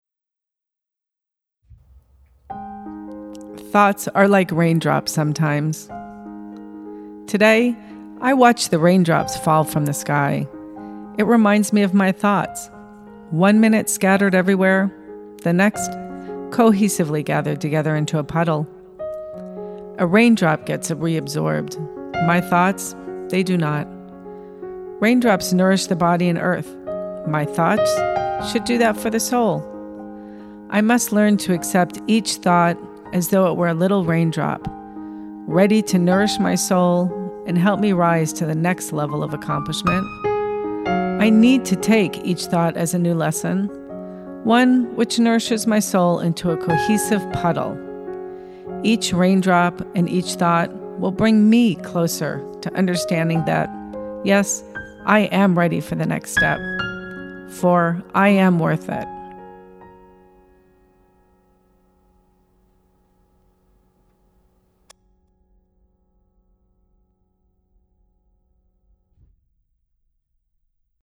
/ Poetry, Uncategorized / By